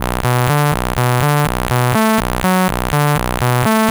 Bass Power B 123.wav